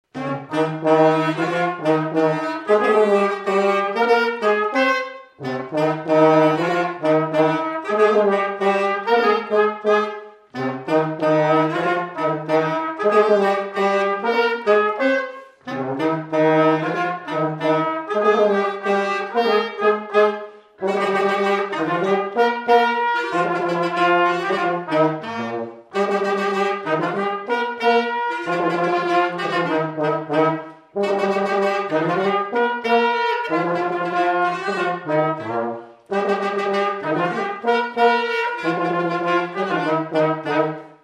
Polka
Chants brefs - A danser
Résumé instrumental
Pièce musicale inédite